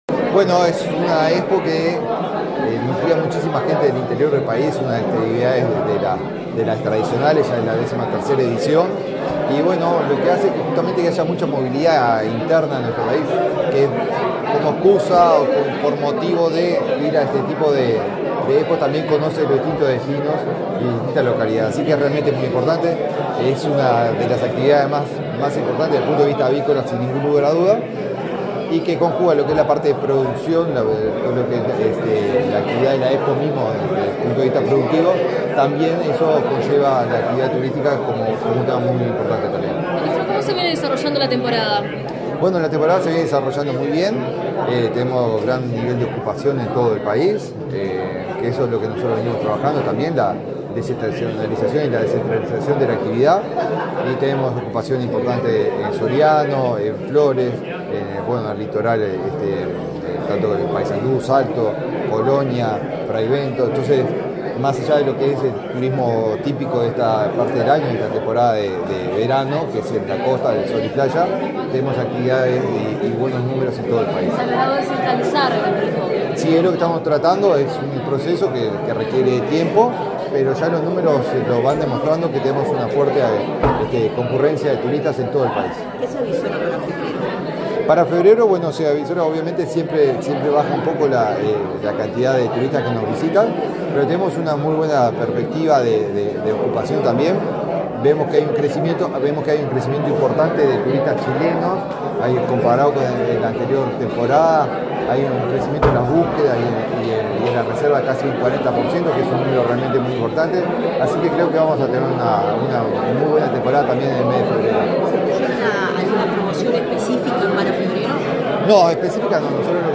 Declaraciones del ministro de Turismo
El ministro de Turismo, Eduardo Sanguinetti, dialogó con la prensa, luego de participar del lanzamiento del festival del Pollo y la Gallina, que se